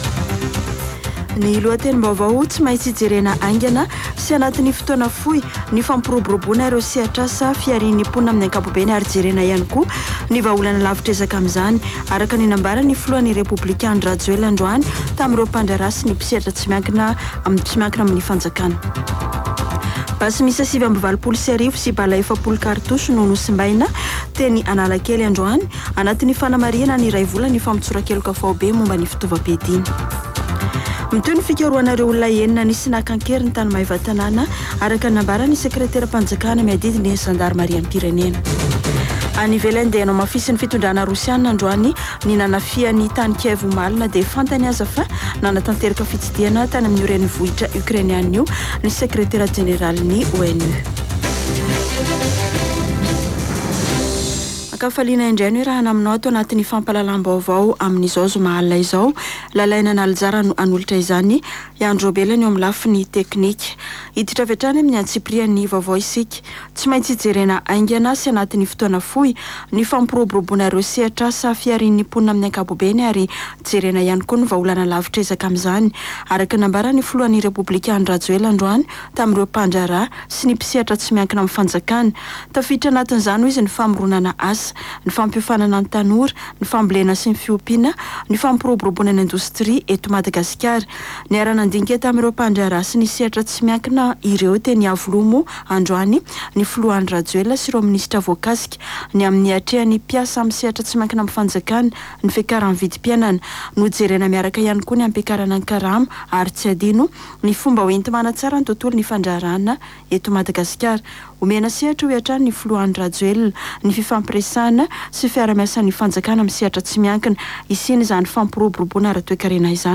[Vaovao hariva] Zoma 29 aprily 2022